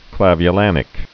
(klăvy-lănĭk)